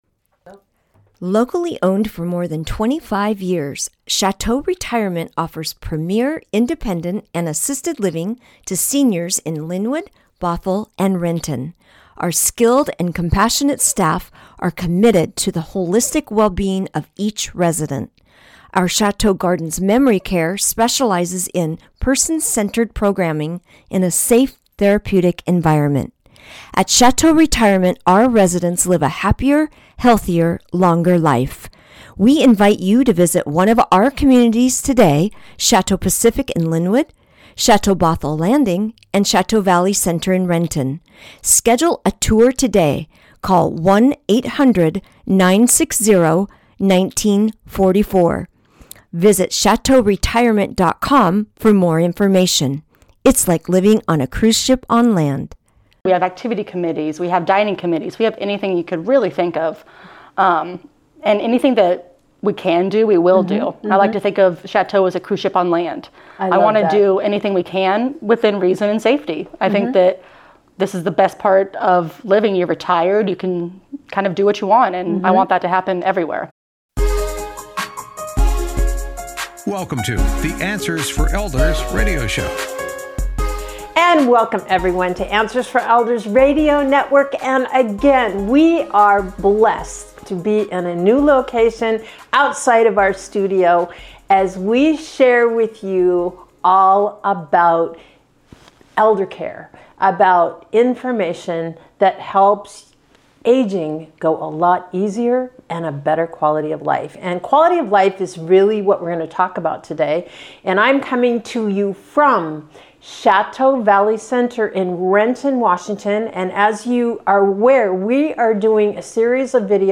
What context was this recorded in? This episode was recorded at Chateau Valley Center in Renton, Washington.